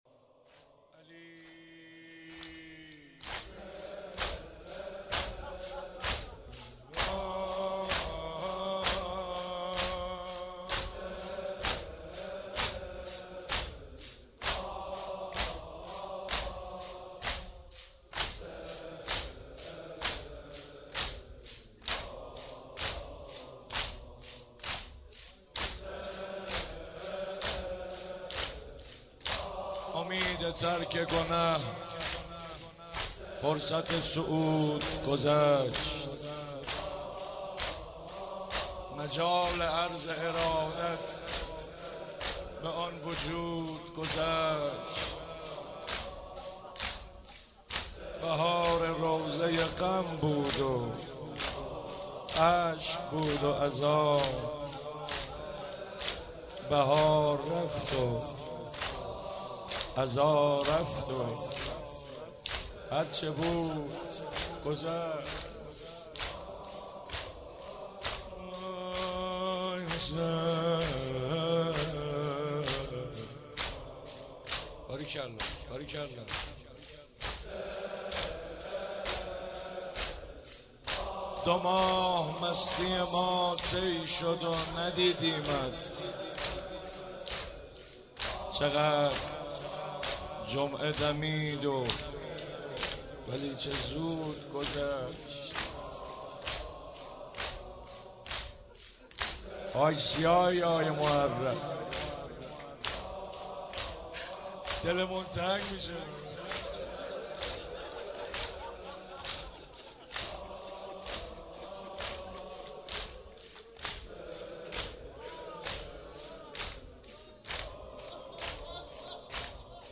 وداع با محرم و صفر - زمینه - امید ترک گنه فرصت سعود گذشت